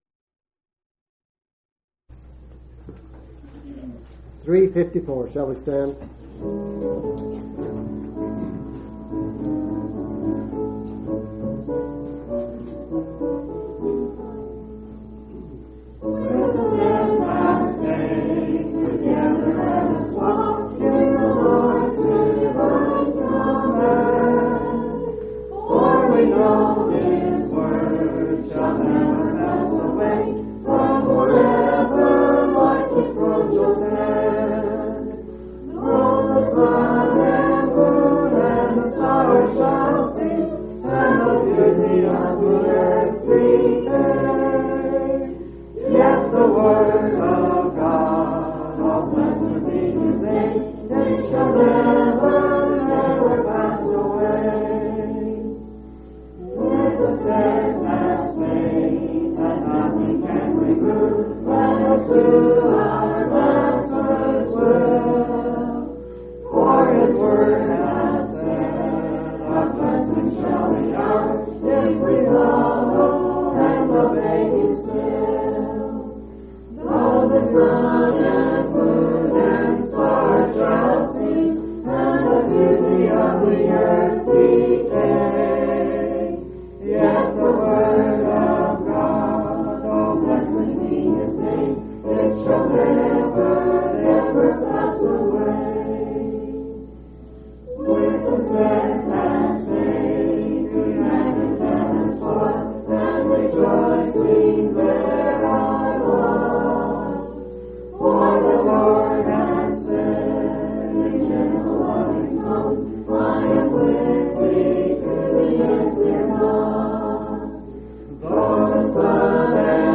6/14/1997 Location: Colorado Reunion Event